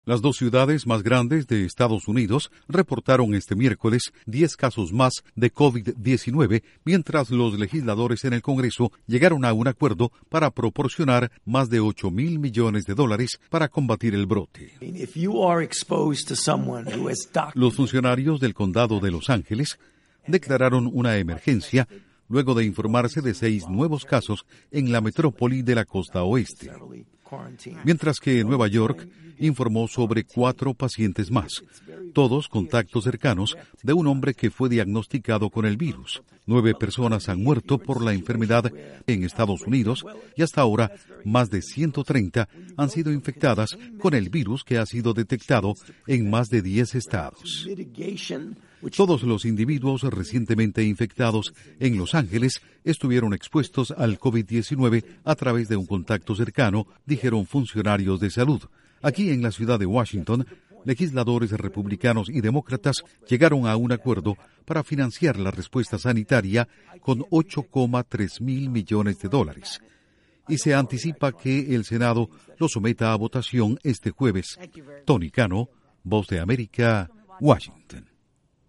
Nuevos casos de COVID-19 en Los Ángeles y Nueva York; en Washington legisladores llegan a acuerdo sobre fondos para combatir el brote. Informa desde la Voz de América en Washington